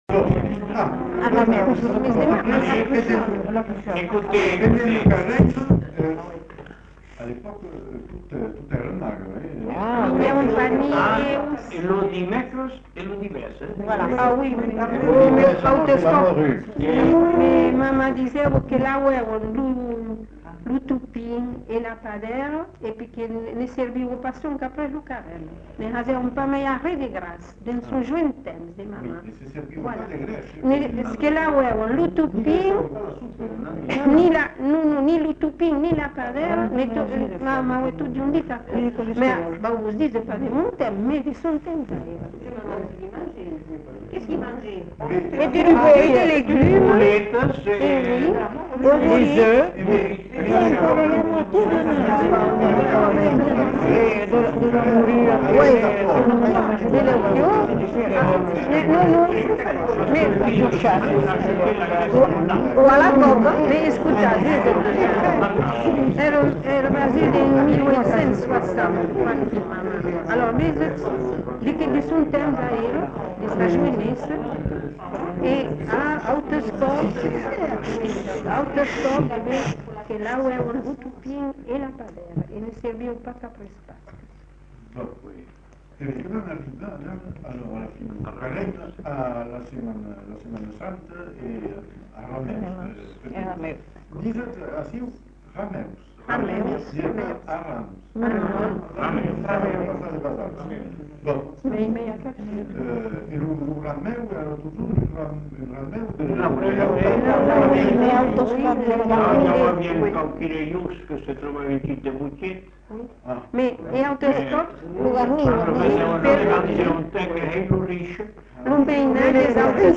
Lieu : Bazas
Genre : témoignage thématique
Descripteurs : carême ; Rameaux ; semaine sainte ; Pâques Instrument de musique : claquoir